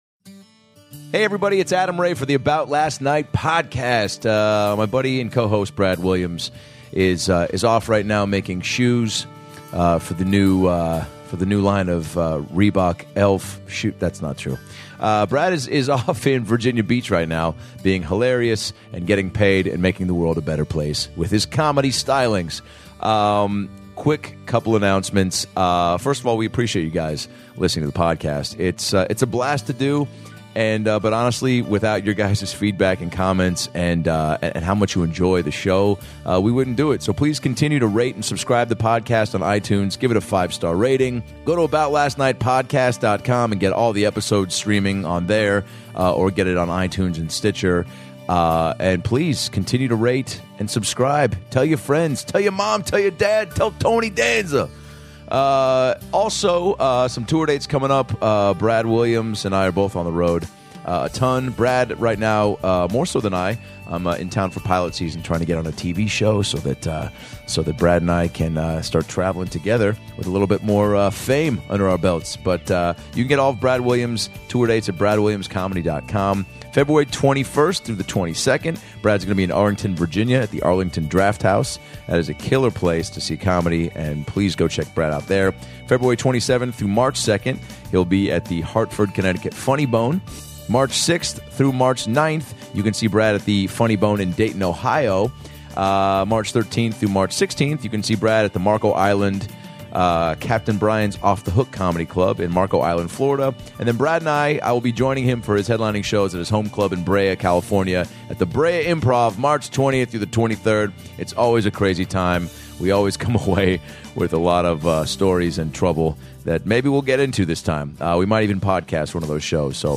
Comedian Brittany Furlan joins us for an episode of this podcast you won't soon forget.